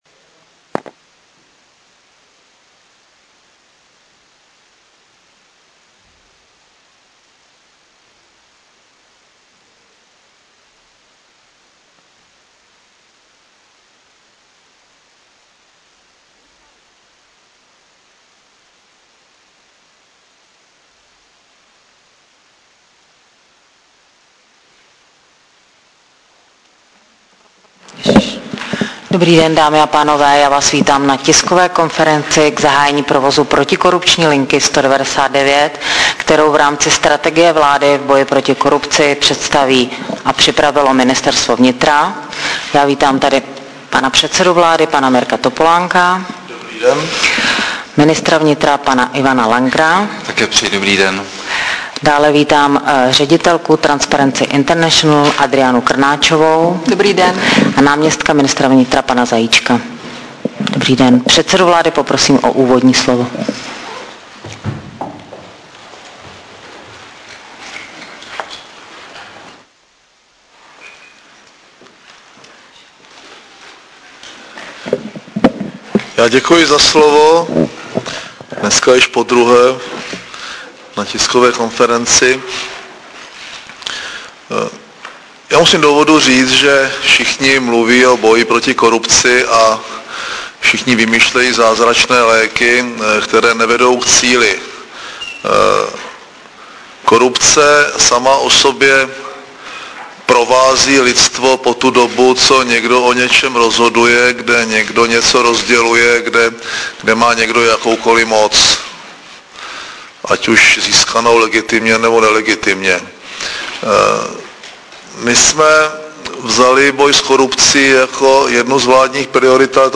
Tisková konference k zahájení pilotního provozu protikorupční linky 199